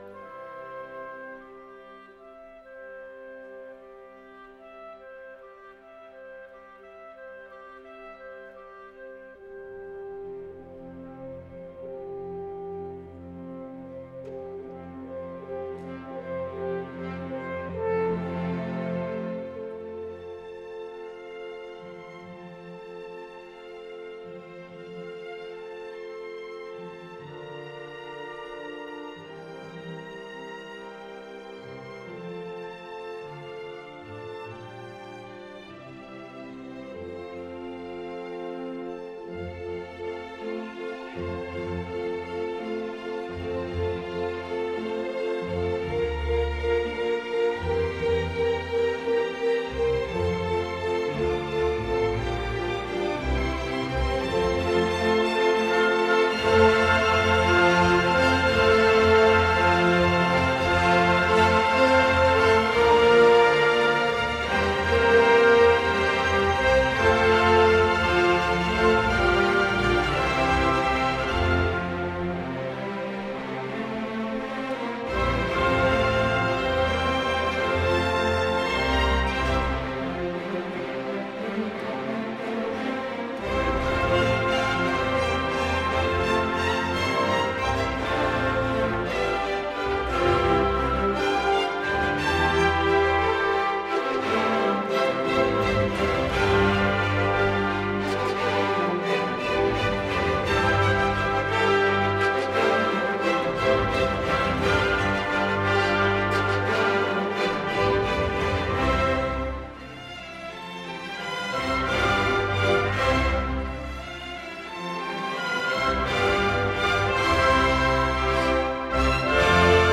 Sonata rondo